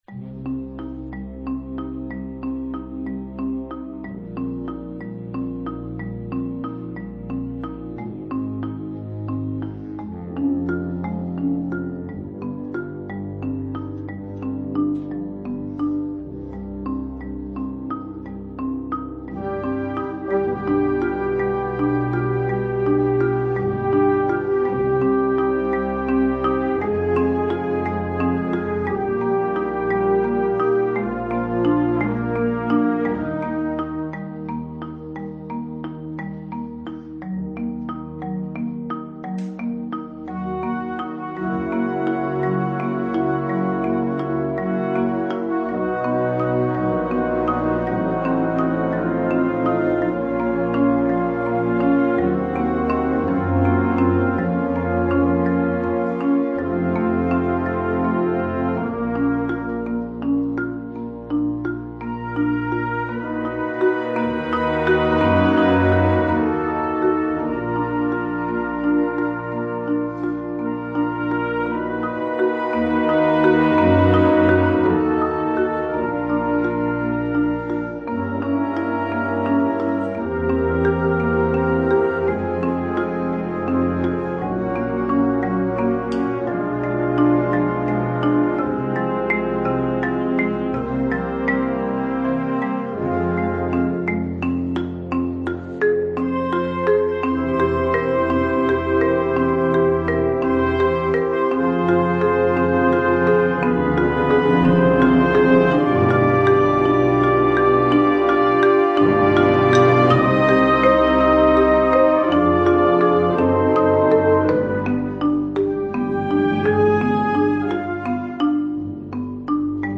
Voicing: Marimba w/ Band